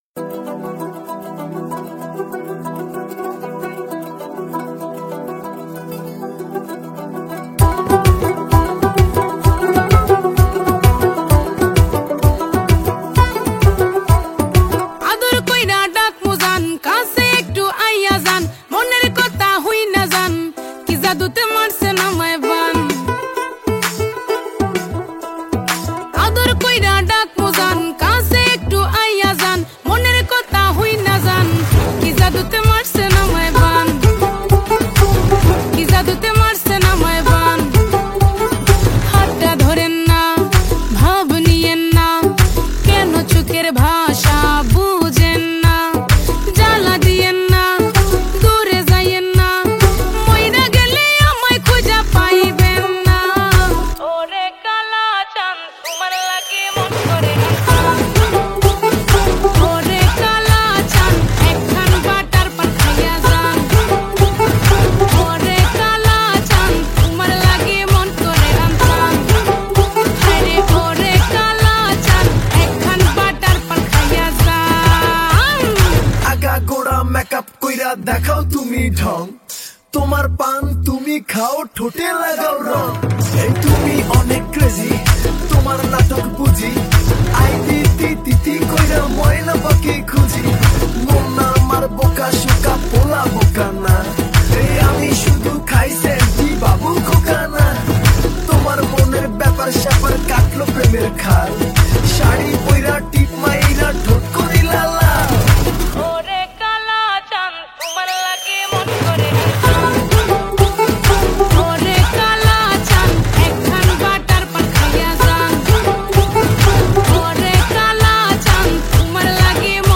Bengali Songs Album